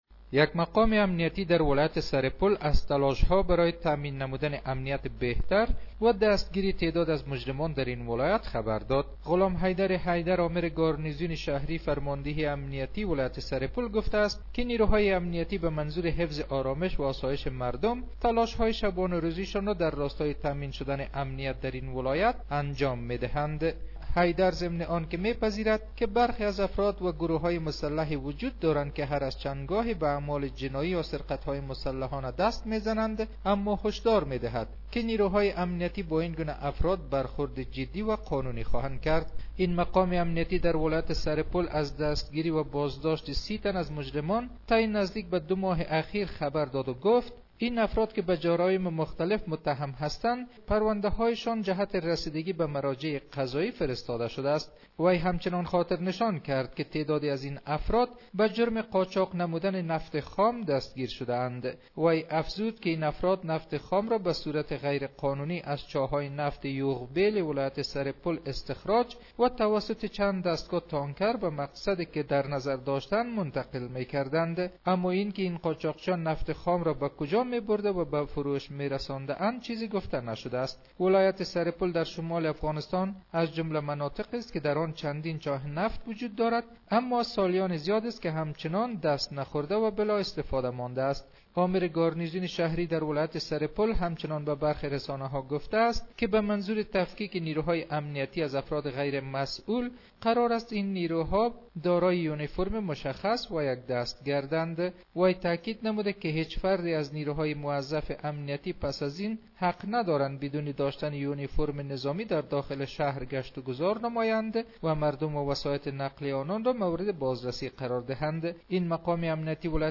جزئیات بیشتر این خبر در گزارش